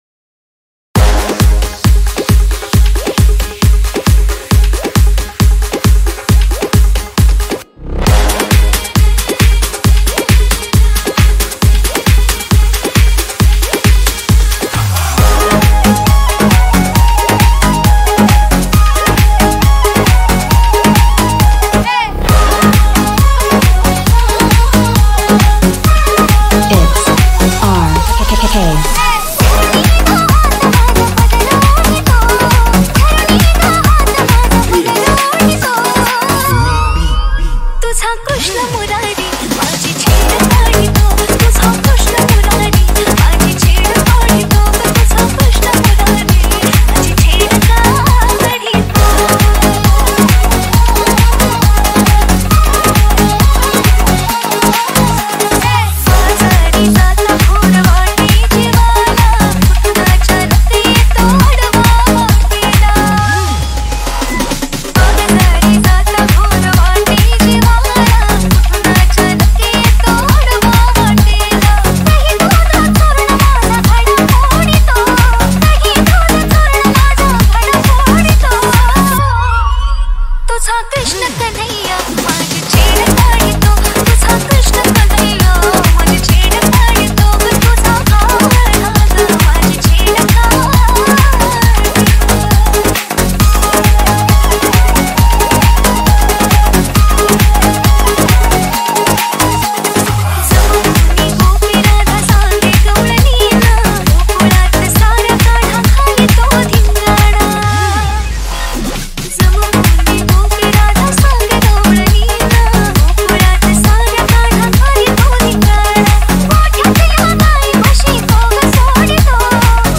• Category: Marathi Djs Remix